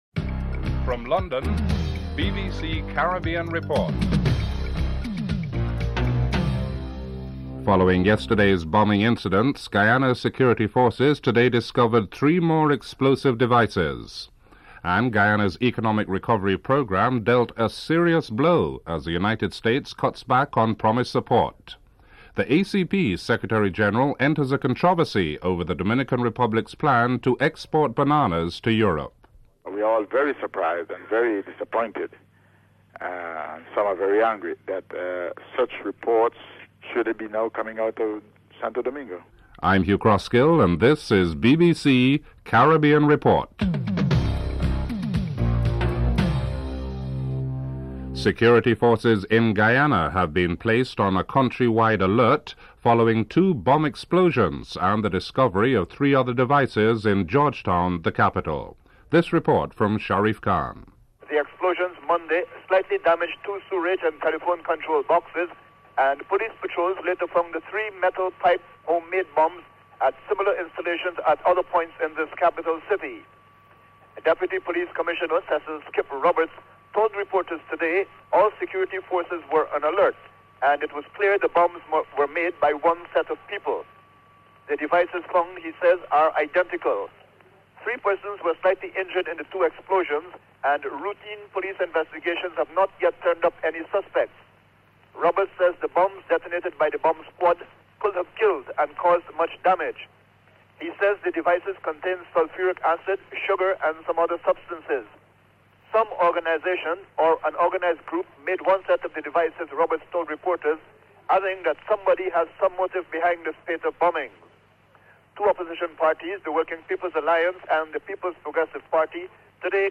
3. Hangings in Guyana. Interview with Clive Abdullah, Bishop of Trinidad and Tobago (02:17-04:08)
7. Commemoration of the 44th birthday of Bob Marley. Features an interview with Bob Marley in 1980 and Marcia Griffiths (12:48-14:50)